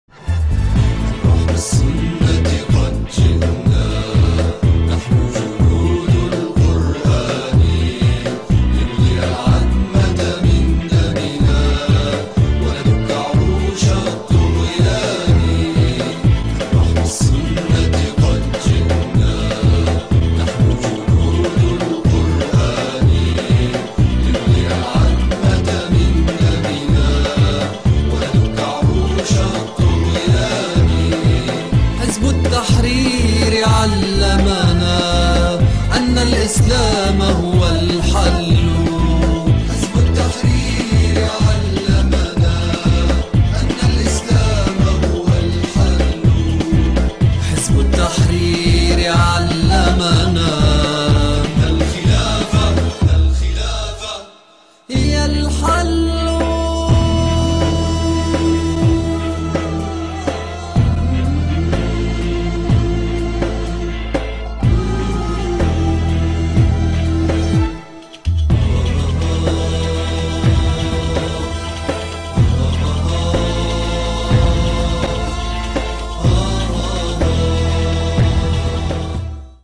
أنشودة